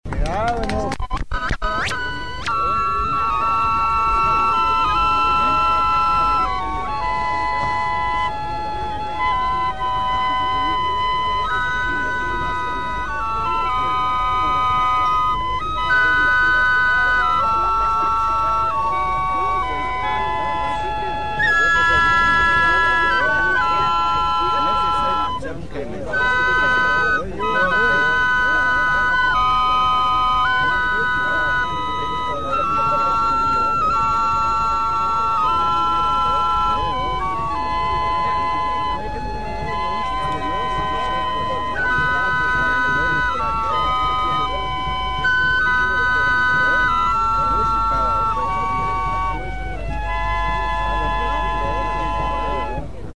Geigenlandler
Pfeifertag 1975 auf der Blaa-Alm
Jodler mit Schleunigen
Pfeifertag.mp3